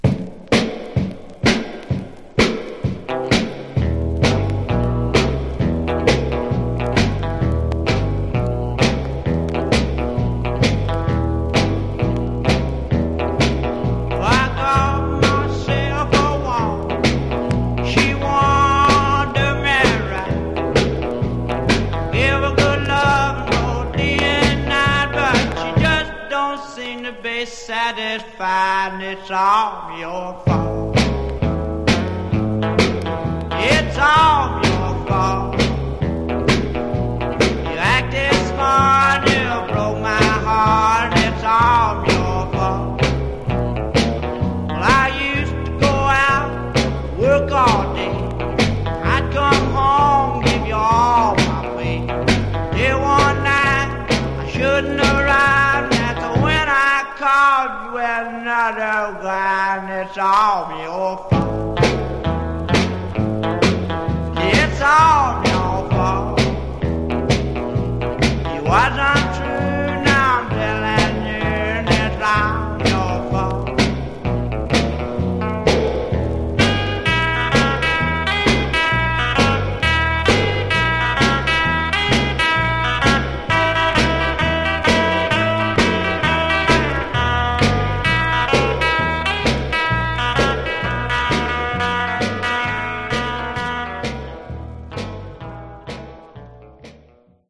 Genre: Rockabilly/Retro